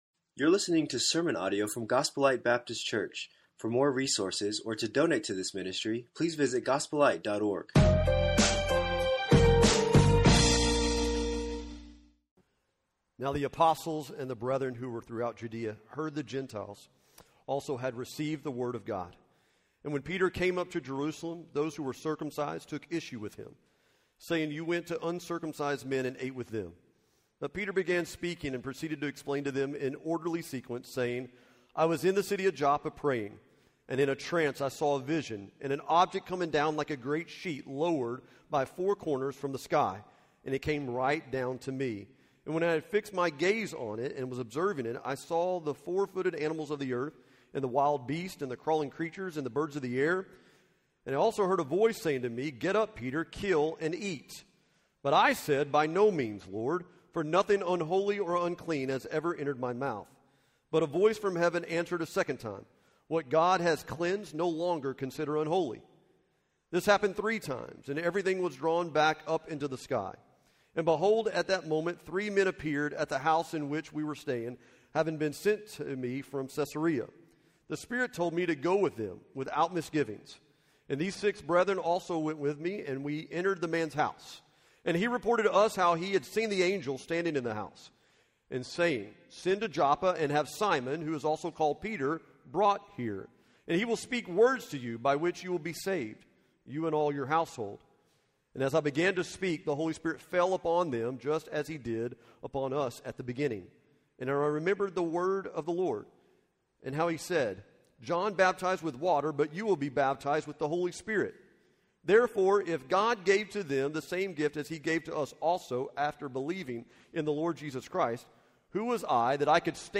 Acts of the Holy Spirit - Sermon 8